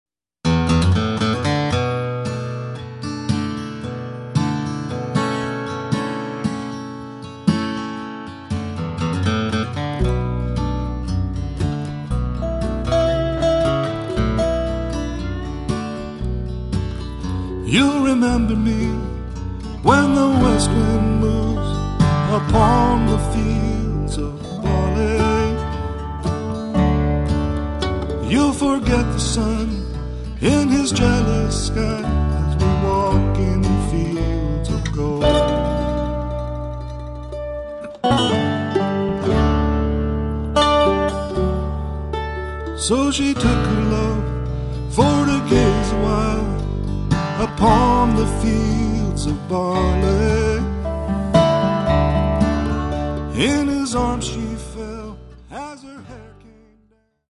--bluegrass - folk - original music